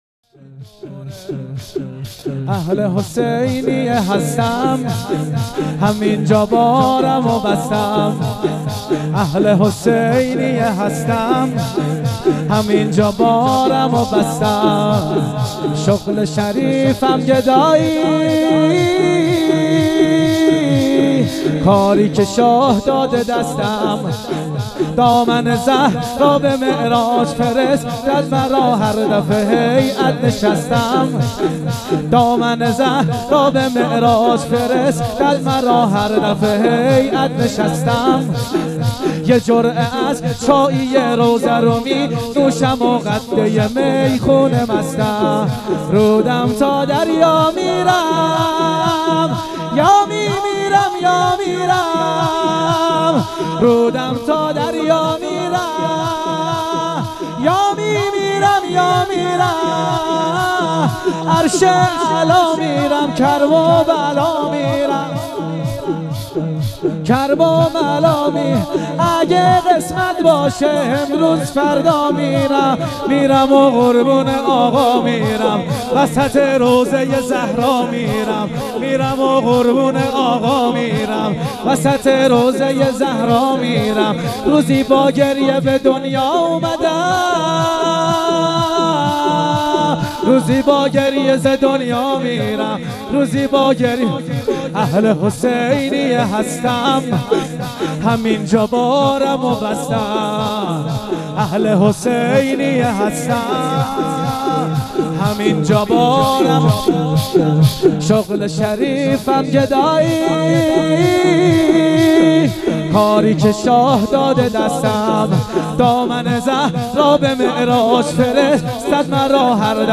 مراسم هفتگی روضه انصار العباس(ع)
در حسینیه صالحین برگزار شد
زمینه و شور